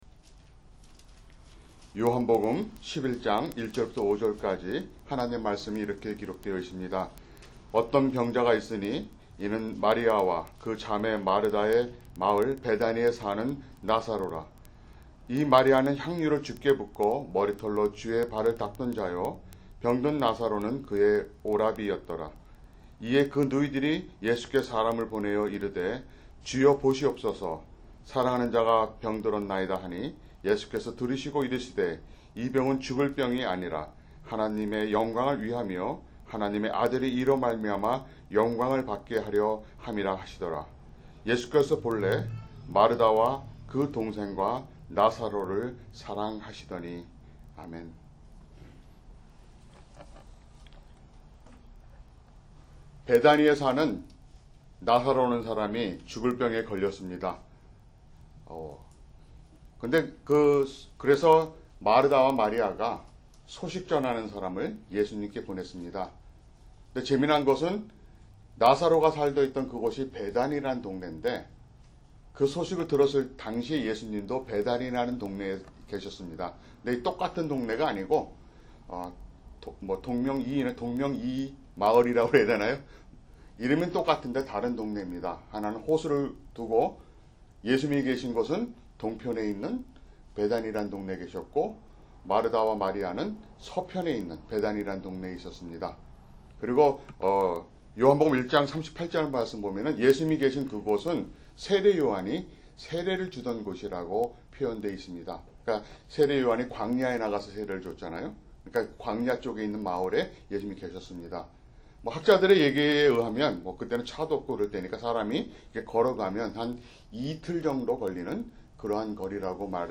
[주일 설교] 요한복음 15:1-17
[English Audio Translation] John 15:1-17